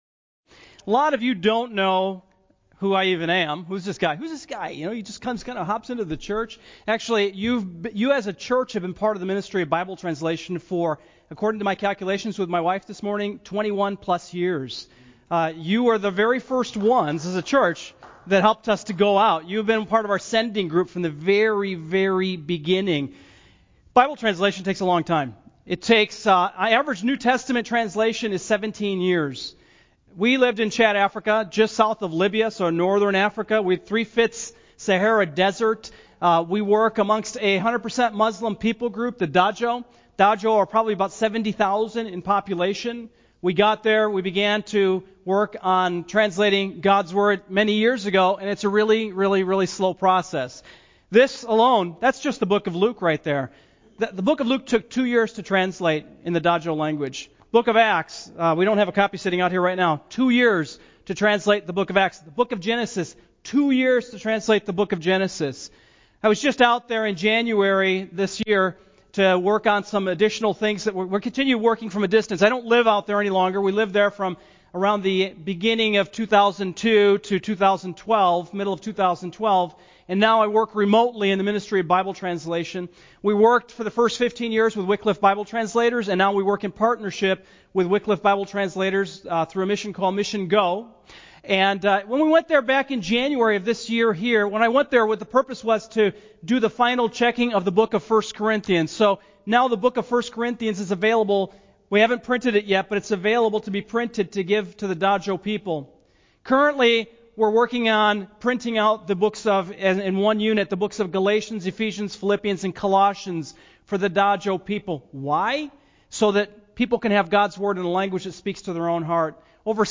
Passage: Colossians 2:1-23 Service Type: Sunday morning